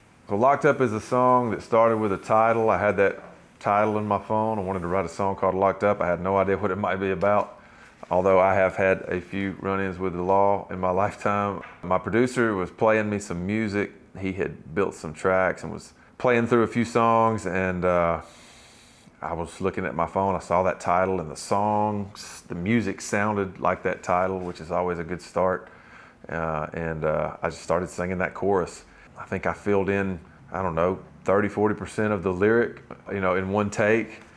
Sam Hunt talks about starting to write his new song, "Locked Up."